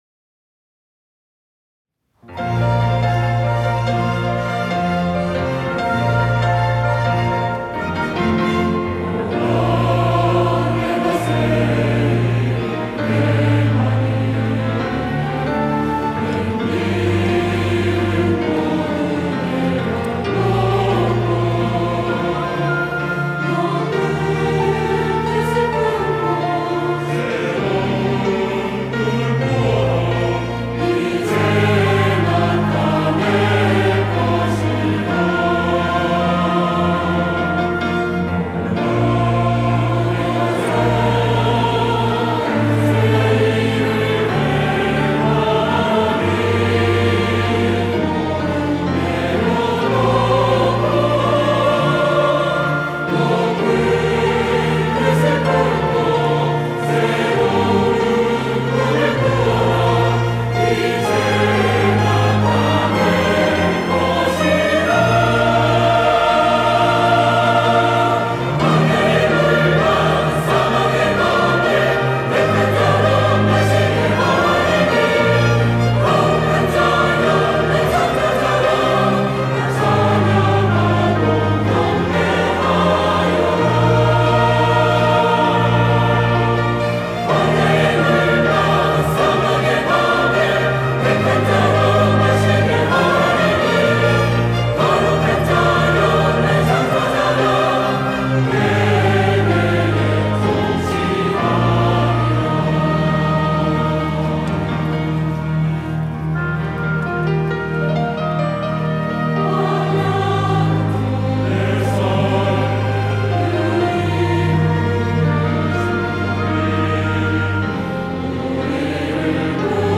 호산나(주일3부) - 주를 보라
찬양대